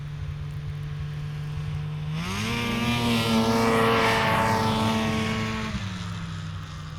Snowmobile Description Form (PDF)
Subjective Noise Event Audio File (WAV)